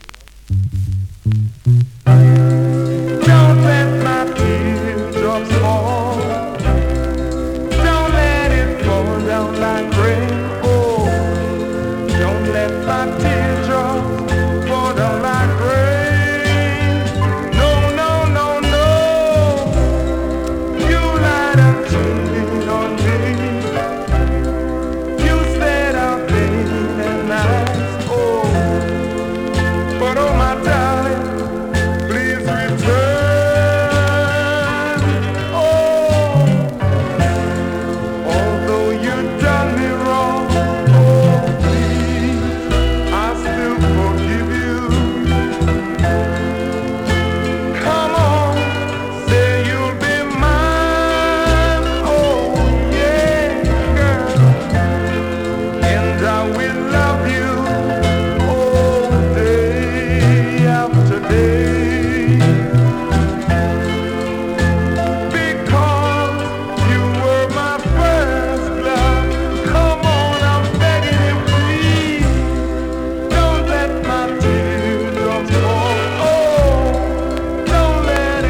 ※やや音にごり有
スリキズ、ノイズそこそこありますが